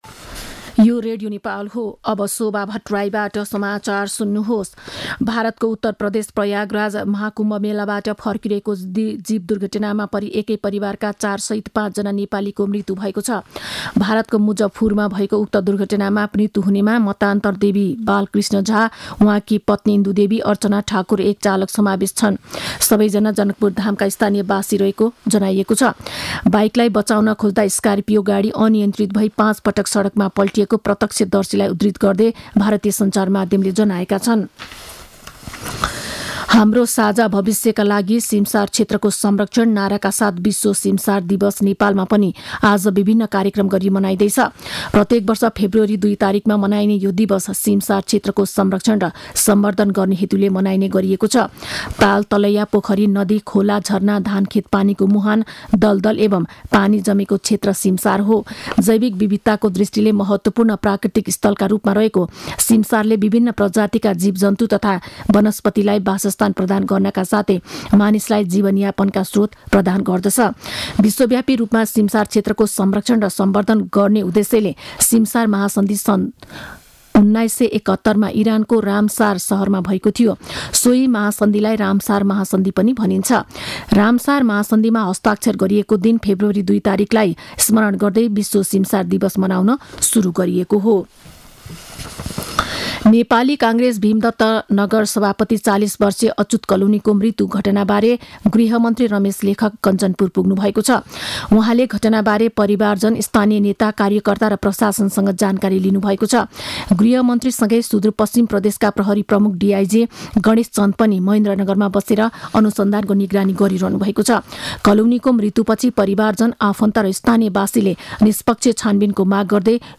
12-am-news-.mp3